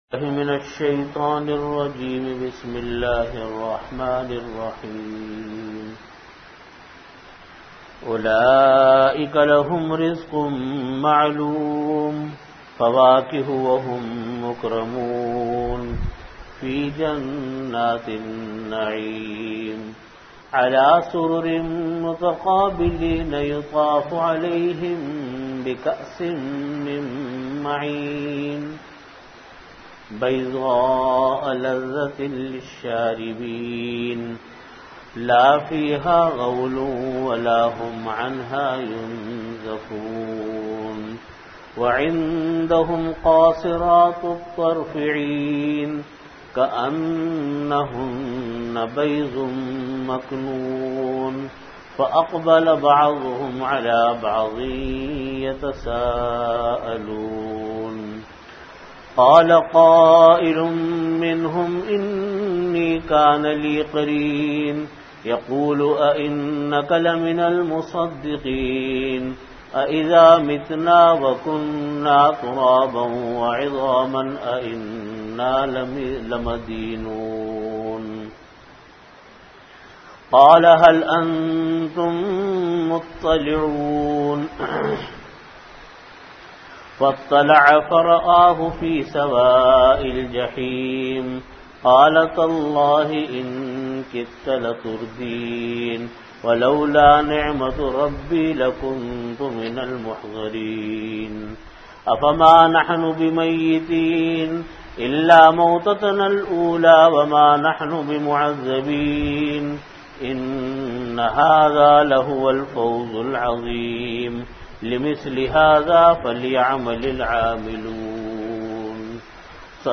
Tafseer Surah Saaffaat - III
Time: After Asar Prayer Venue: Jamia Masjid Bait-ul-Mukkaram, Karachi